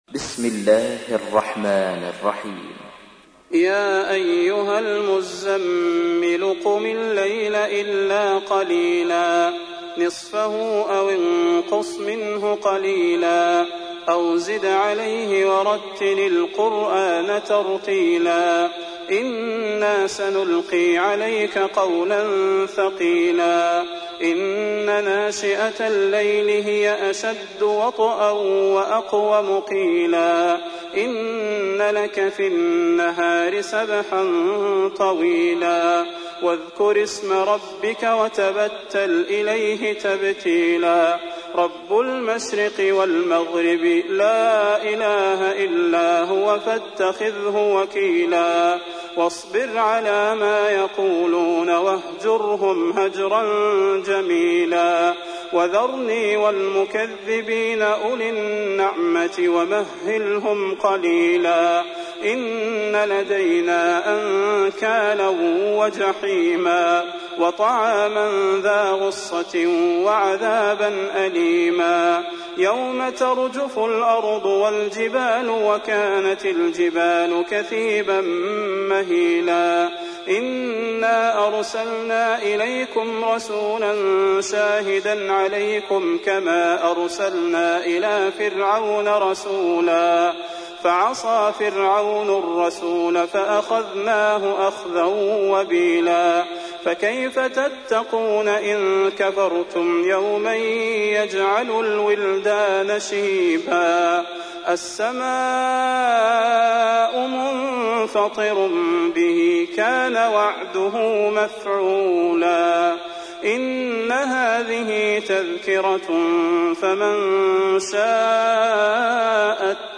تحميل : 73. سورة المزمل / القارئ صلاح البدير / القرآن الكريم / موقع يا حسين